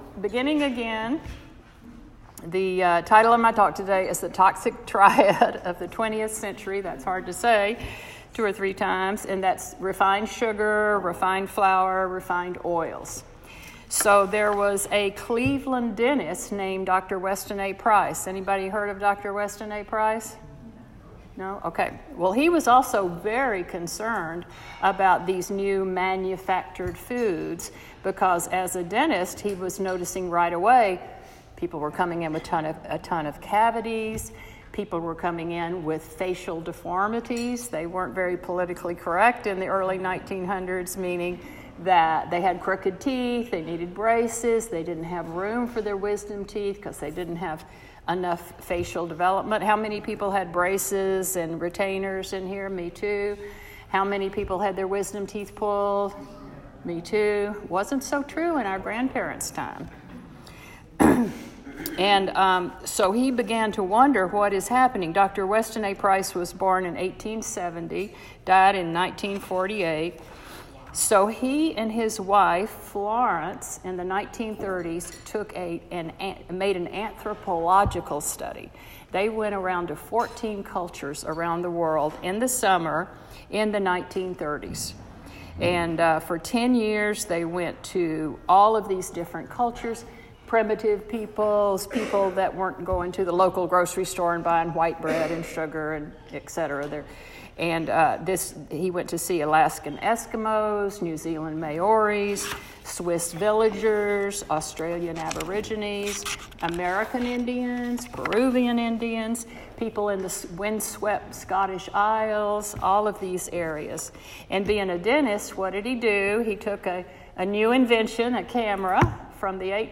Listen to the recorded audio of the talk.